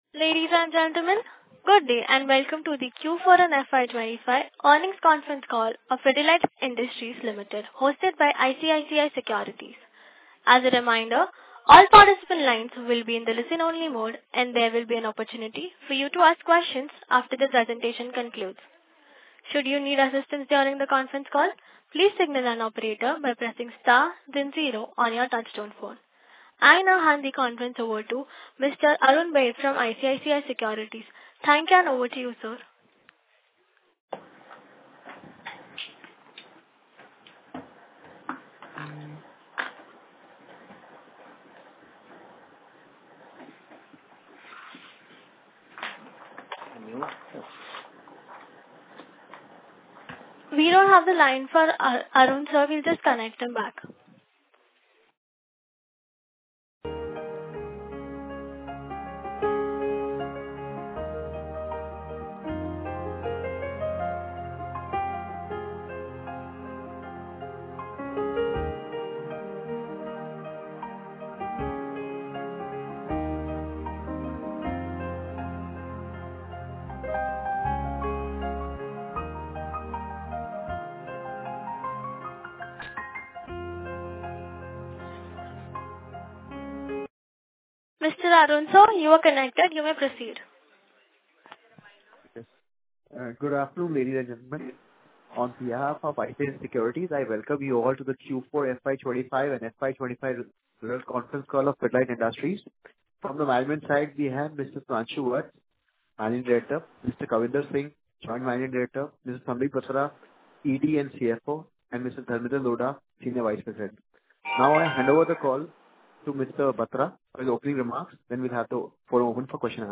Quarterly Results Download PDF Press Release Download PDF Analyst Meet Invite Download PDF Analyst Meet Presentation Download PDF Analyst Audio Call Recording Download Audio Analyst Audio Call Transcript Download PDF